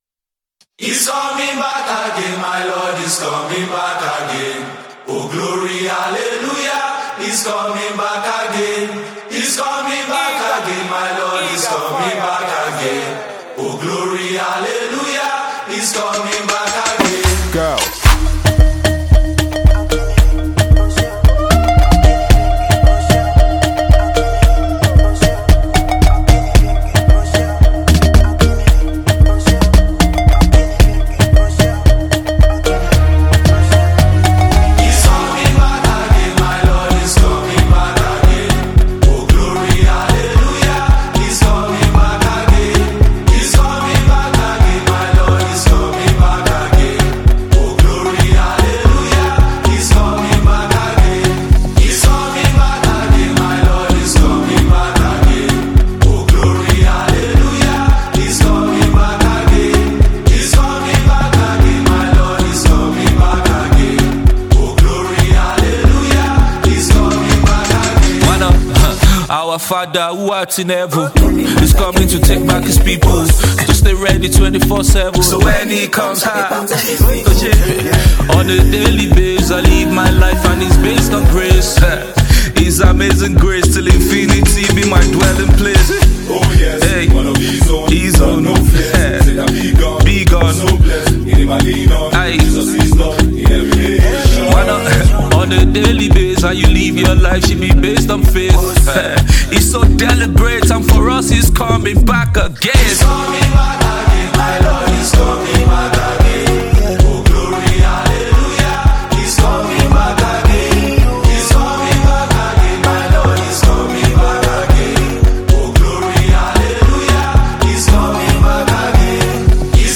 charming, instantly catchy vibe
Gospel track
lively rhythms and infectious energy